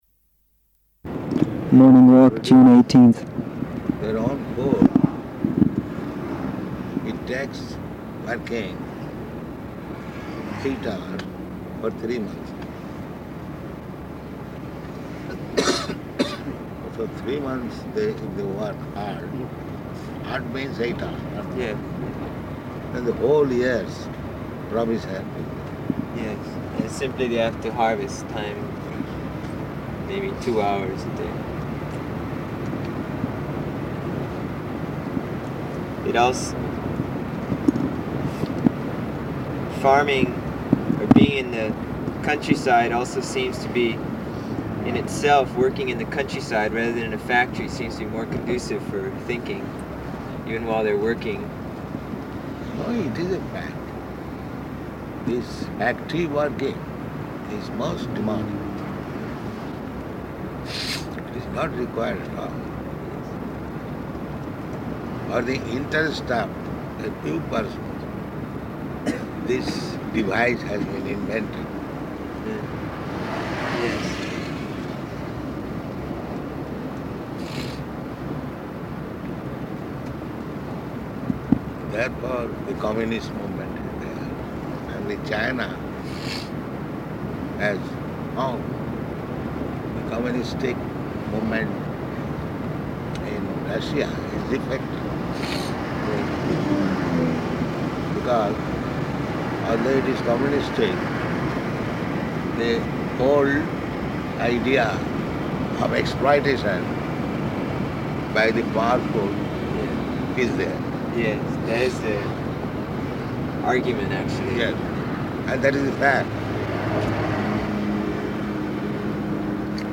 -- Type: Walk Dated: June 18th 1975 Location: Honolulu, Audio file
[break] [in car] Prabhupāda: ...their own food, it takes working eight hours for three months.